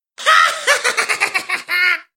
Рингтон Смех мелкого существа
Звуки на звонок